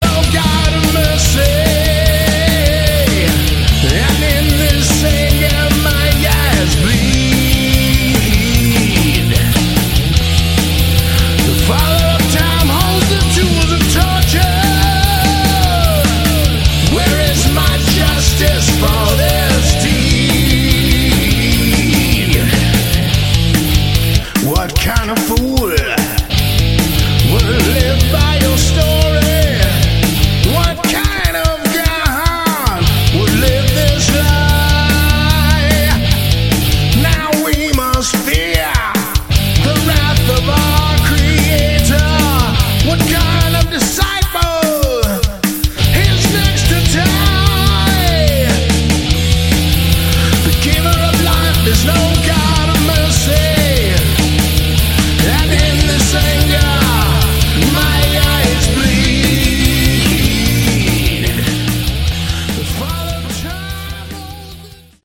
Category: Hard Rock
guitars
vocals